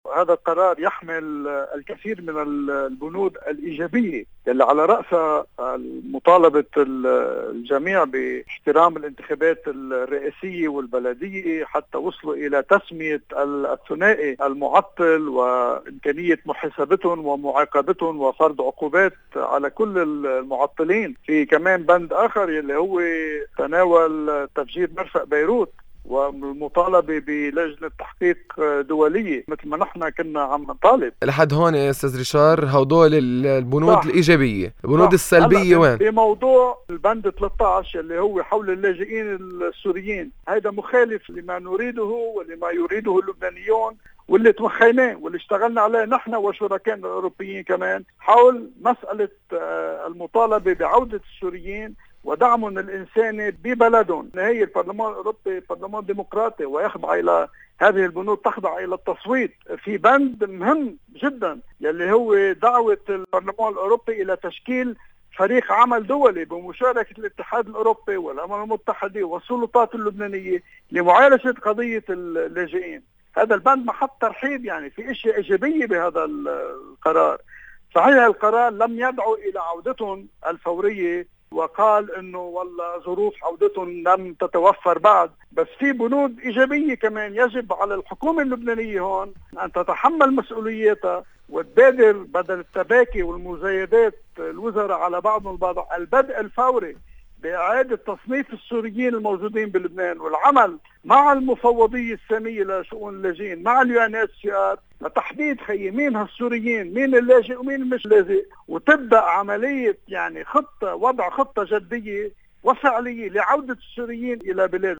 علّق رئيس جهاز العلاقات الخارجية في القوات الوزير السابق ريشار قيومجيان عبر “لبنان الحرّ” على القرارات الأخيرة للبرلمان الأوروبي. وقسم قيومجيان بين الإيجابيات والسلبيات، مطالباً باعادة تصنيف السورين.